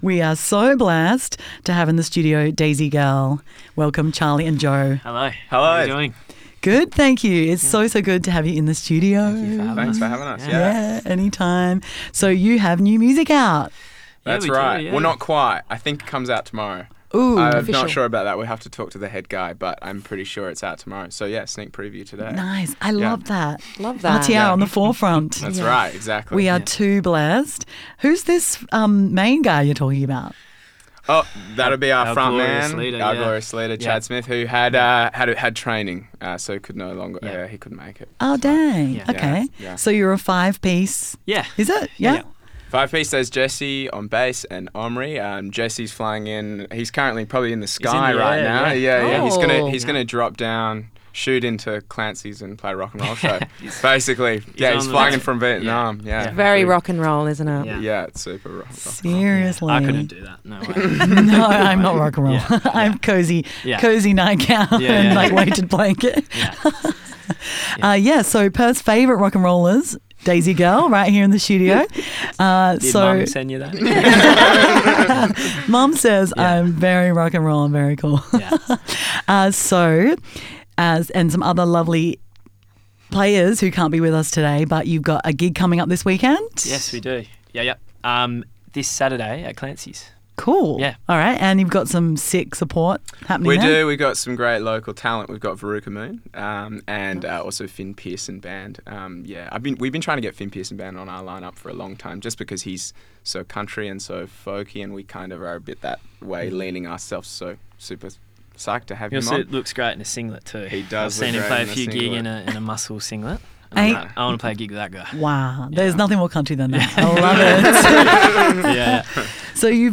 Daisy-Girl-Interview.mp3